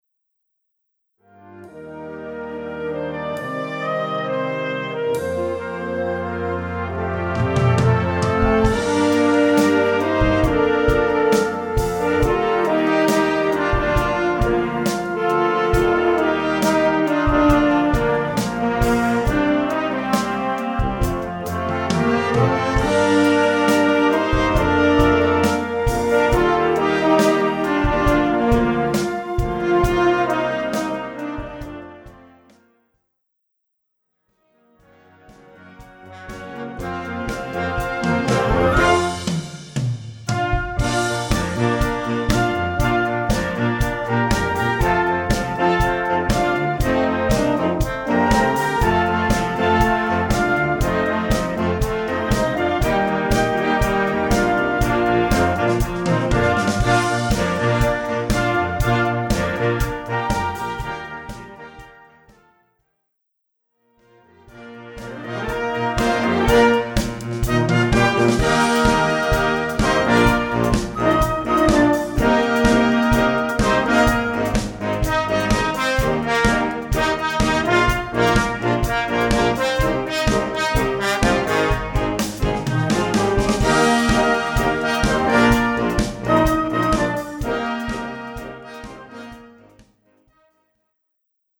Gattung: Modernes Potpourri
Besetzung: Blasorchester